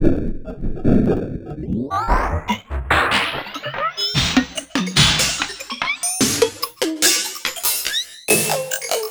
Index of /90_sSampleCDs/USB Soundscan vol.24 - Industrial Loops [AKAI] 1CD/Partition D/01-144ELECTR